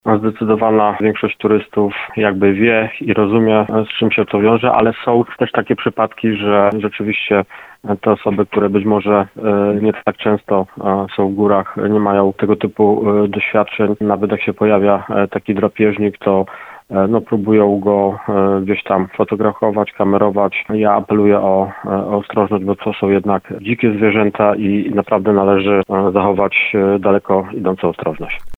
Od około 2 tygodni niedźwiedź pojawia się w okolicy Eliaszówki – mówi nam burmistrz Piwnicznej-Zdroju. W związku z tym Tomasz Michałowski apeluje o nie zostawianie śmieci w tym miejscu, choć na razie zwierzę unikało kontaktu z ludźmi.